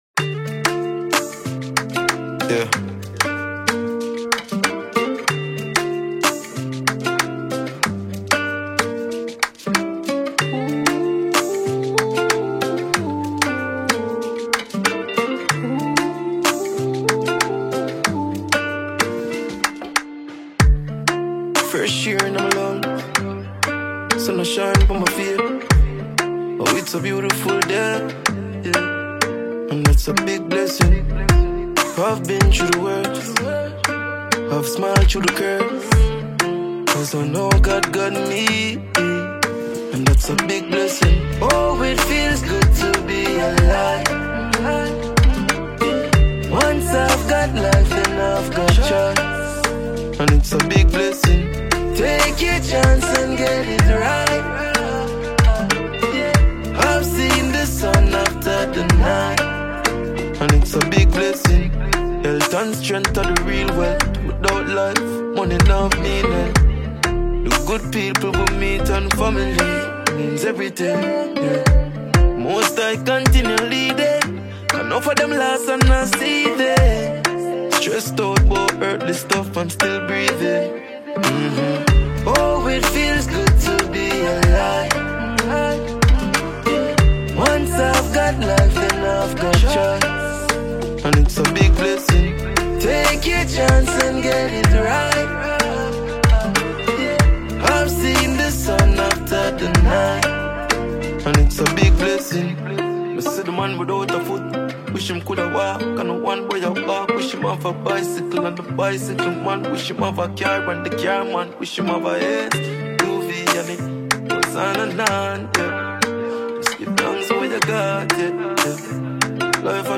With smooth vocals layered over a melodic, mid-tempo riddim